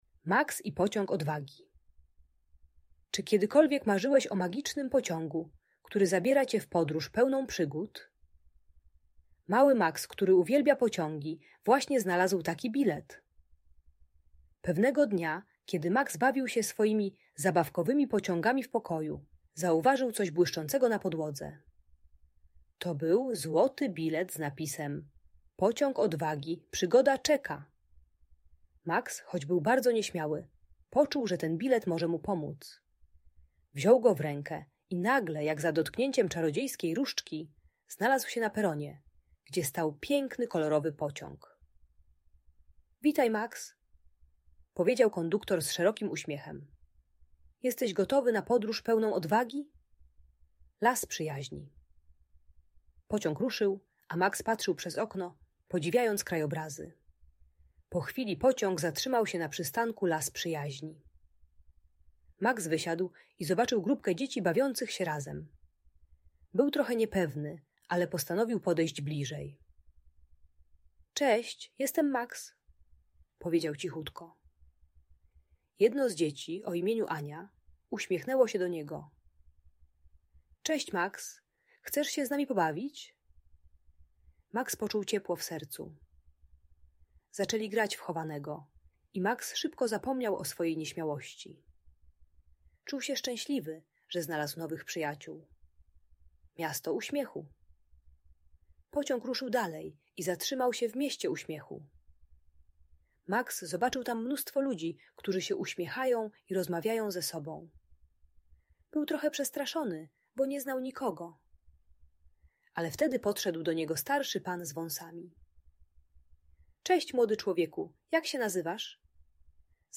Max i Pociąg Odwagi - Audiobajka